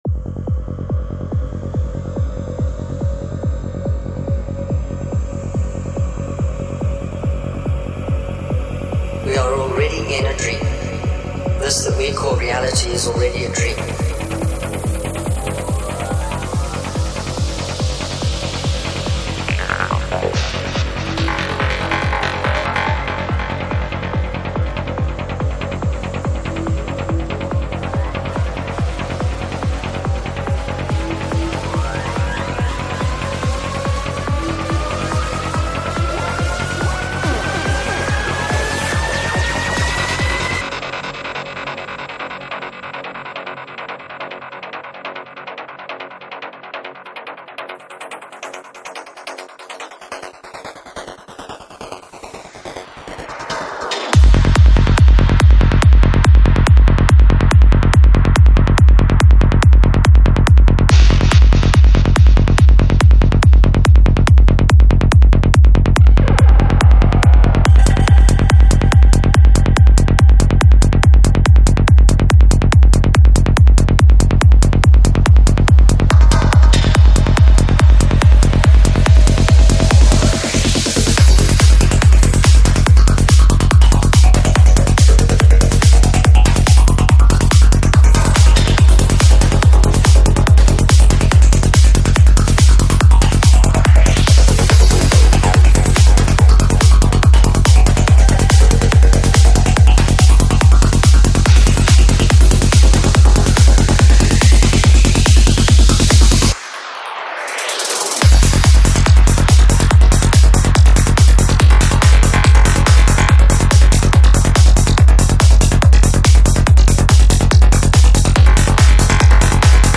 Жанр: Psychedelic
Psy-Trance Скачать 7.49 Мб 0 0 0